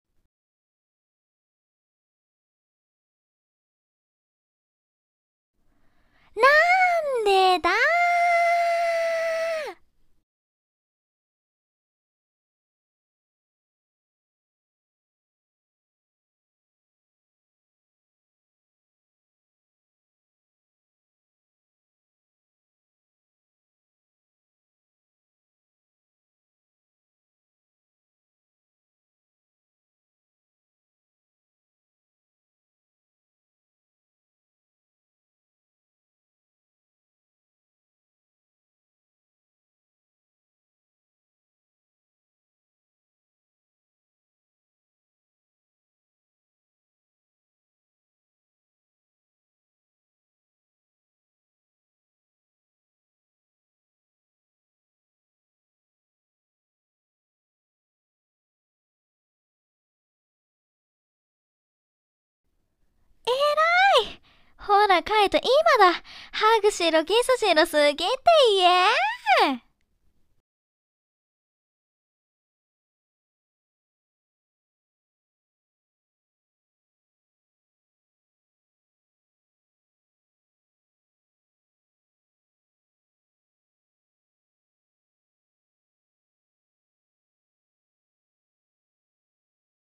【声劇】恋の行方 ~ Part 1-2の覗き見編 ~